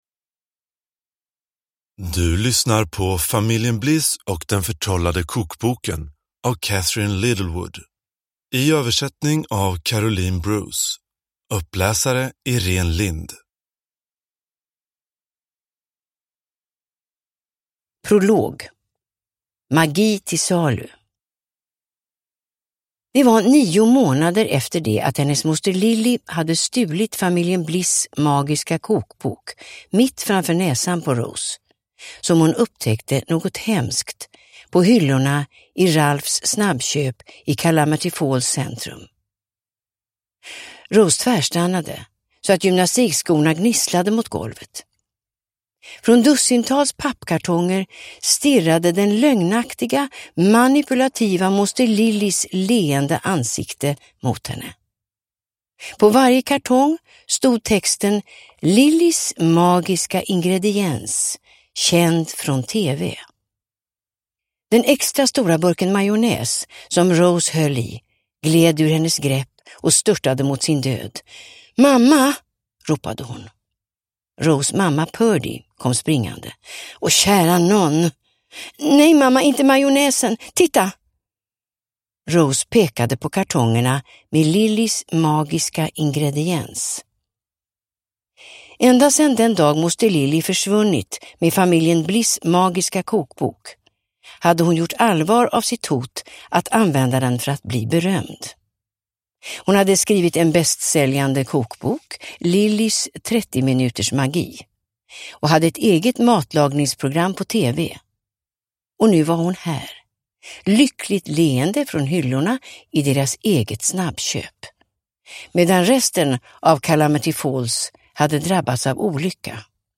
Familjen Bliss och den förtrollade kokboken – Ljudbok – Laddas ner